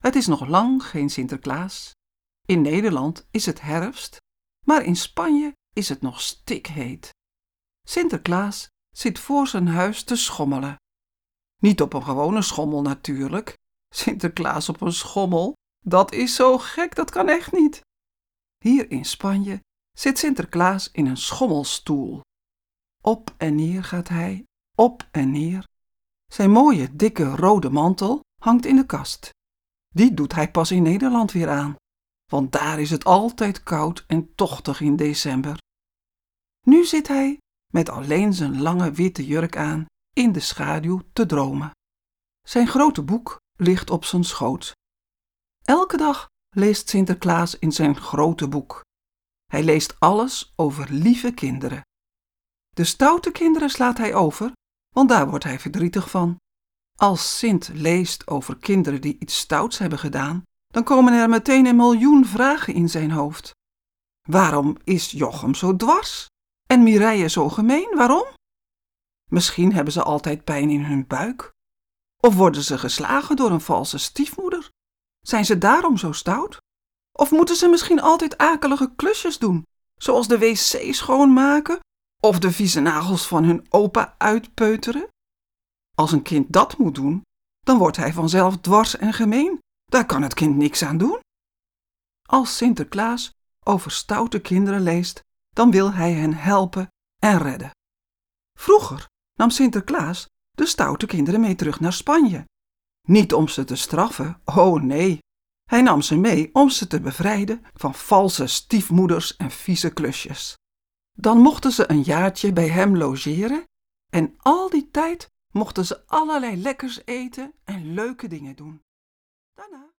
Luister maar naar het beginfragment van het luisterboek ‘Sinterklaas zakt door zijn paard